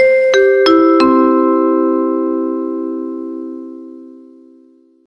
Окунитесь в атмосферу аэропорта с коллекцией звуков объявлений и фоновых шумов.
Звук после объявления: трансляция завершена